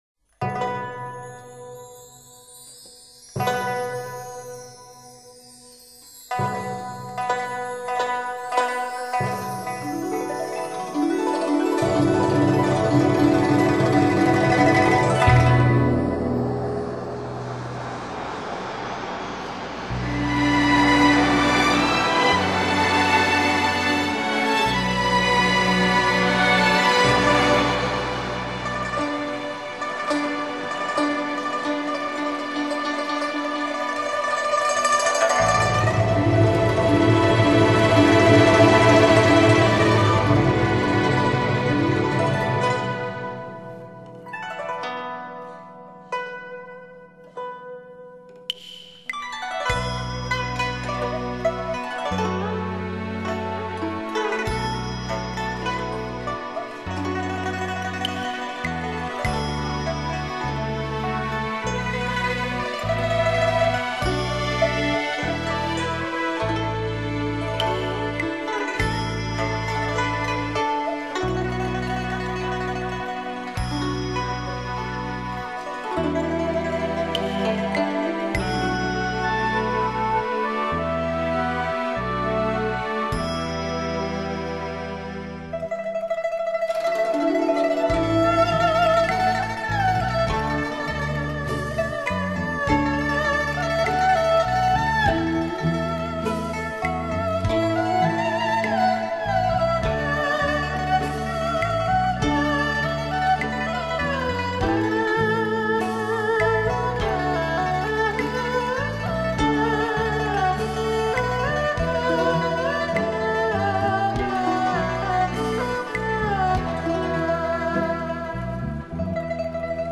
试听：4、轻音乐版